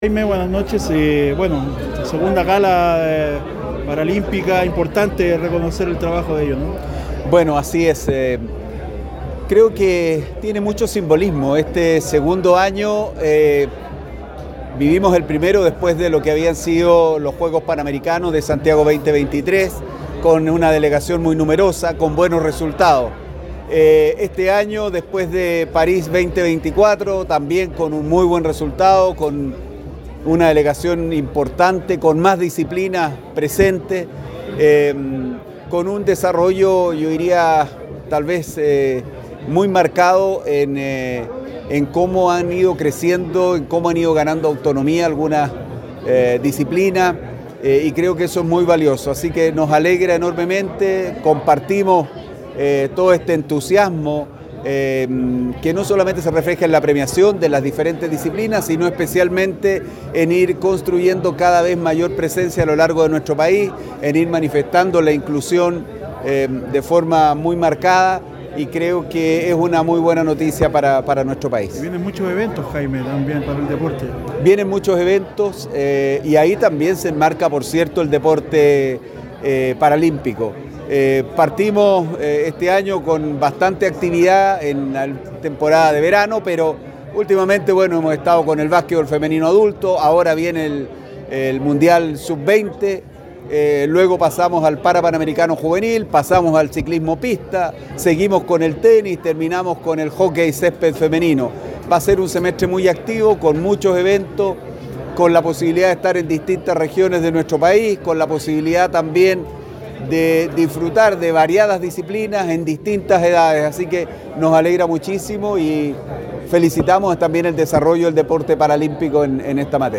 Jaime Pizarro en diálogo con ADN Deportes